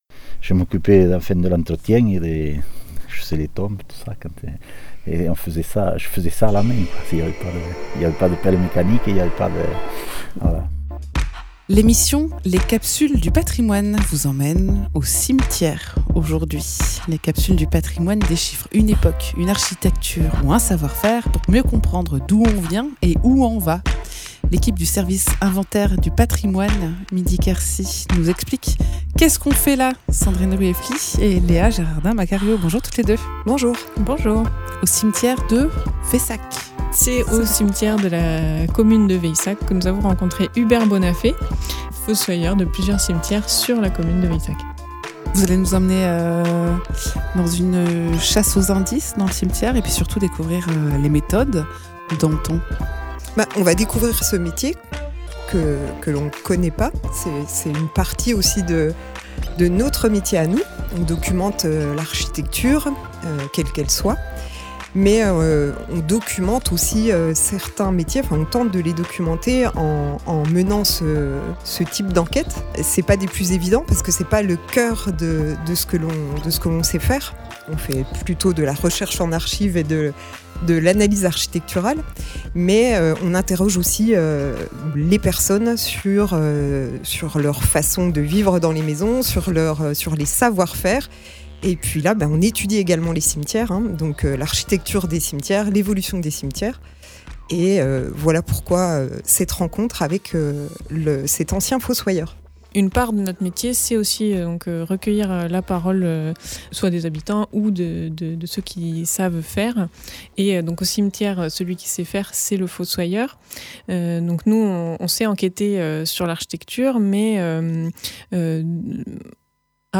L’équipe du service inventaire du patrimoine s’est dotée d’un micro pour recueillir l’histoire de ce métier hors du commun qui a beaucoup changé en quelques décennies.